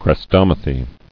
[chres·tom·a·thy]